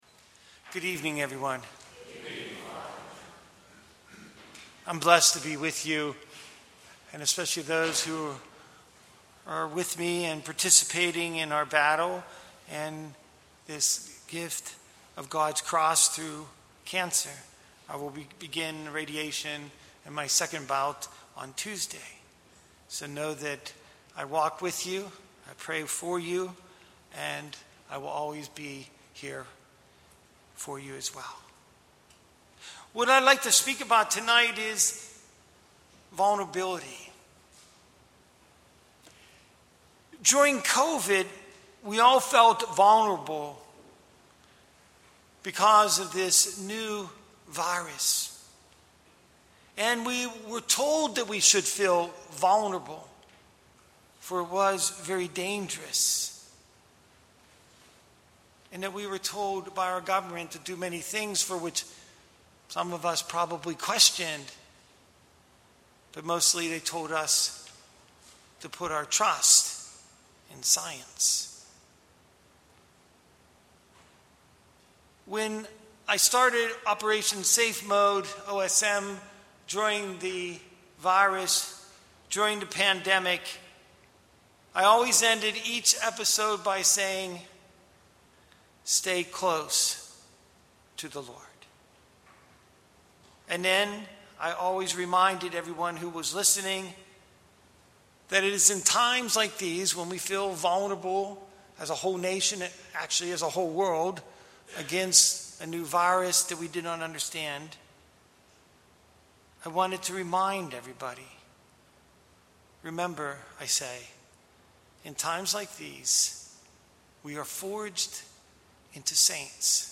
Series: Fall Conference Weekly Homilies